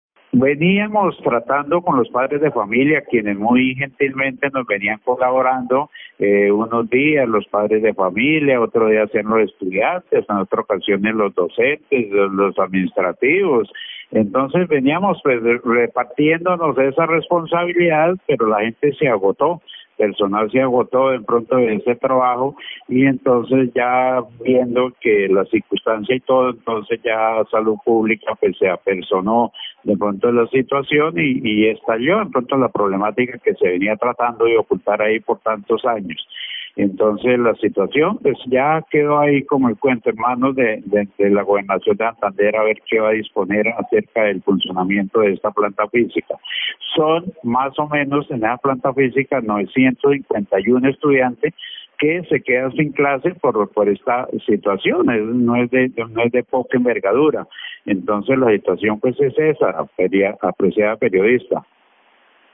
en entrevista con Caracol Radio.